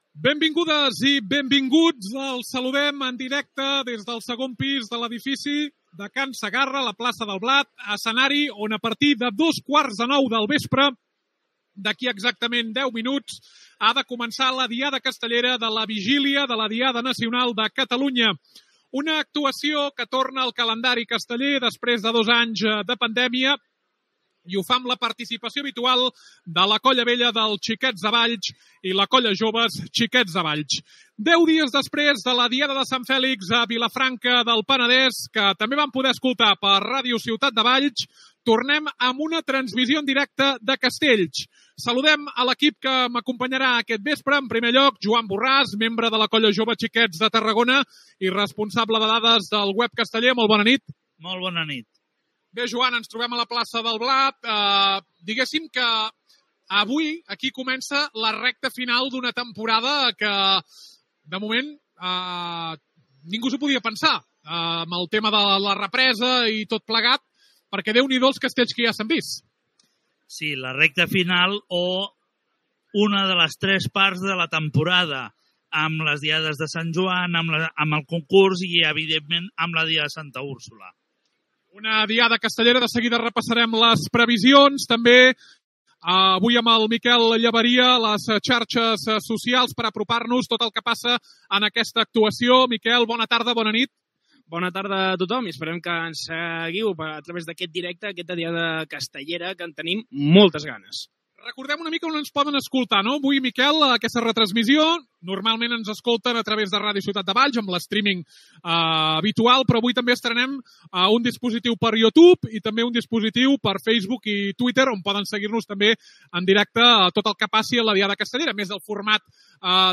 Inici de la transmissió de la diada castellera del 10 de setembre a la plaça del Blat de Valls.
Informatiu